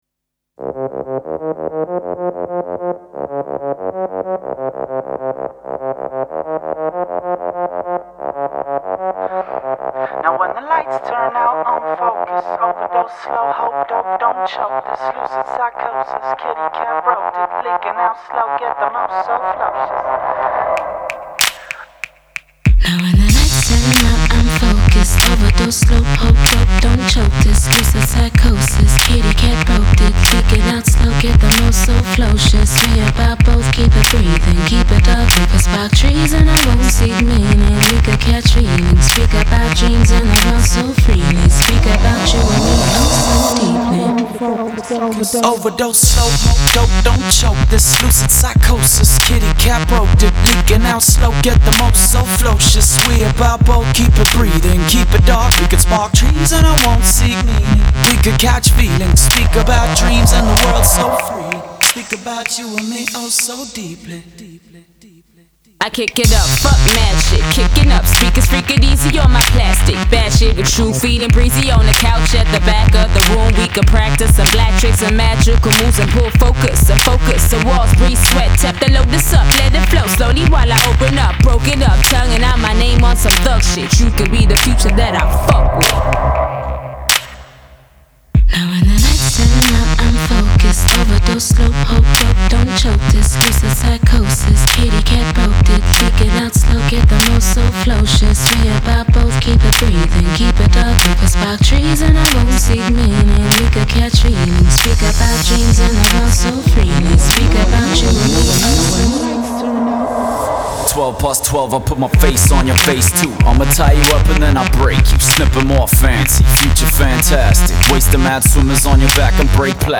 Hip Hop electro toxique aux basses lourdes et puissantes